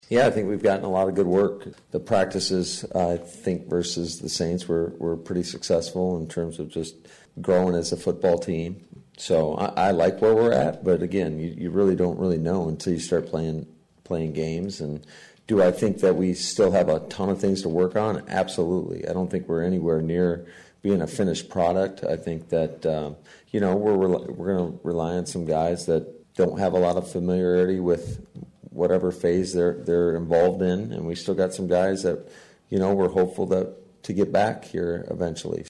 Head Coach Matt LaFleur offered his summation of his 4th training camp in charge of the Pack.